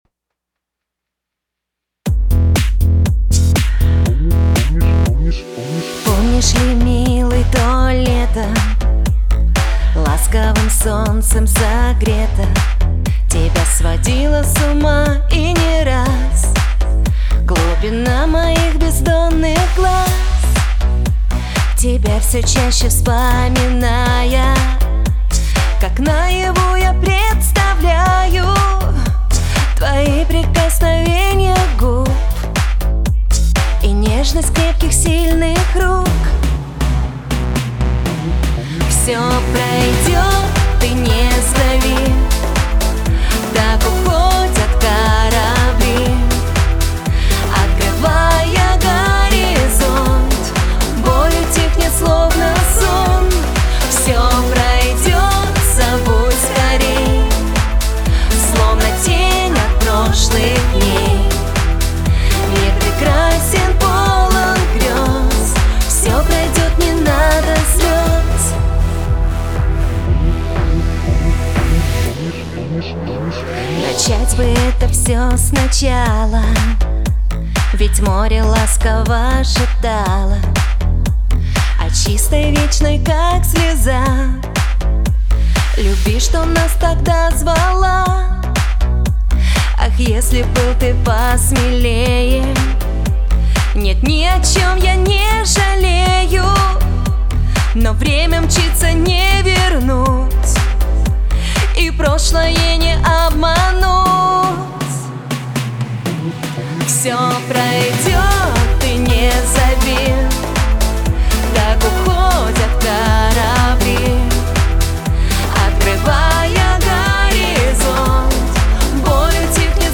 грусть , эстрада